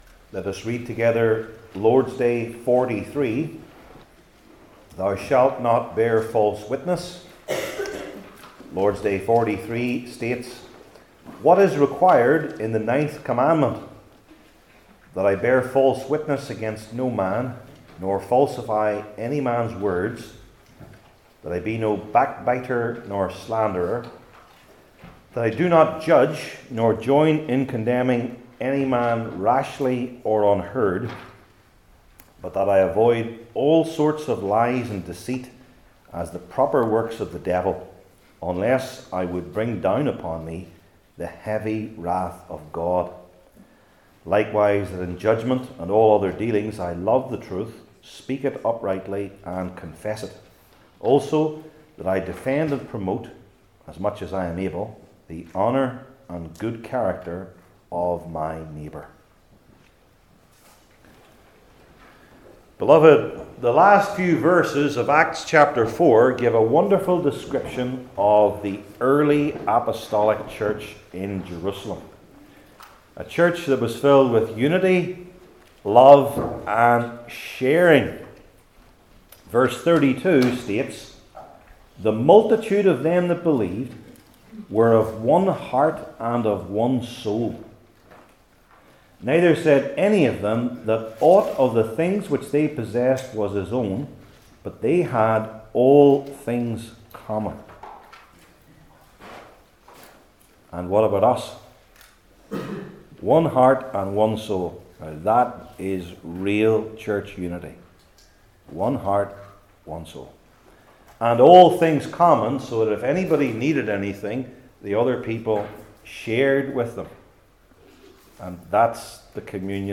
The Ten Commandments Passage: Acts 5:1-16 Service Type: Heidelberg Catechism Sermons I. The Sin II.